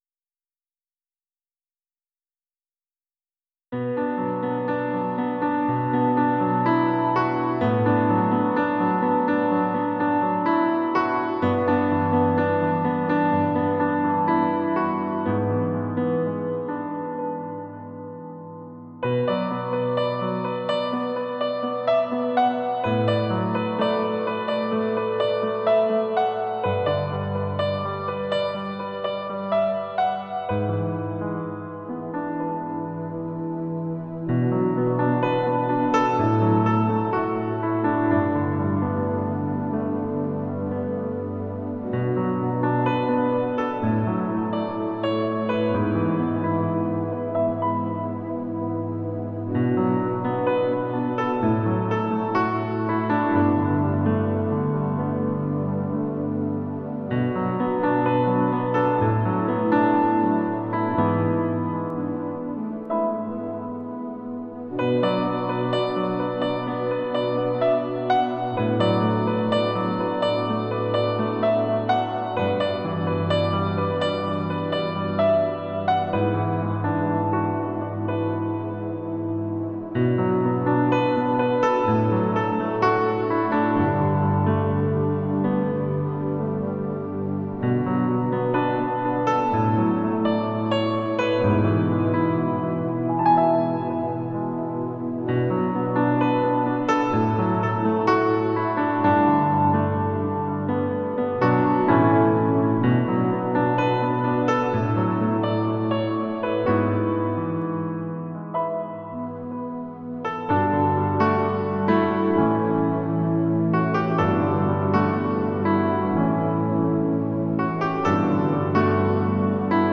I’ve uploaded an instrumental that I’ve written entitled ” Delight Yourself”. It’s based out of Psalm 37:4.